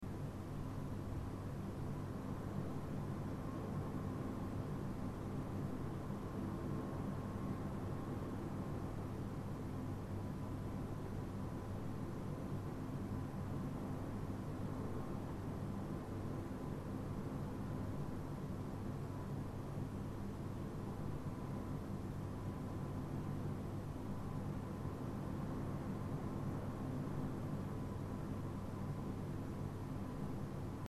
air-conditioning_hotel-room
air conditioning field-recording hotel relaxing room sound effect free sound royalty free Memes